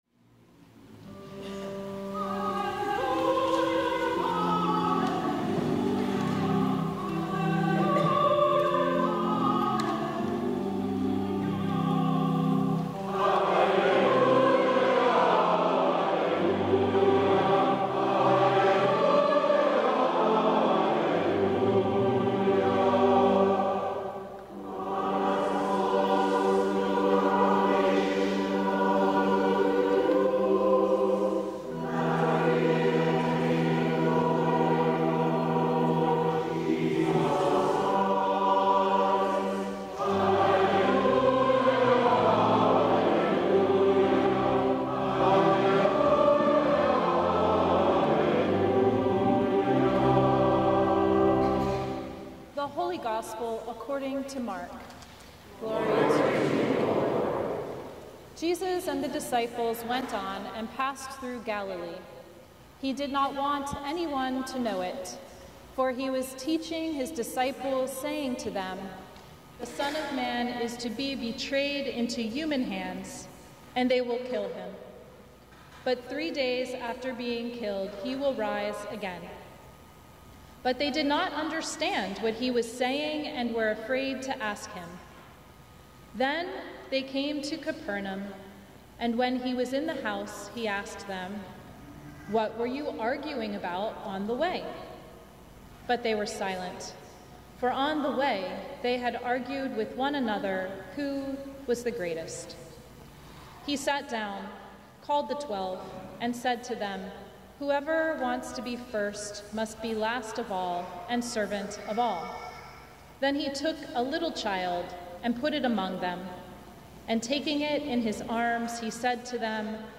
Sermon from the Eighteenth Sunday After Pentecost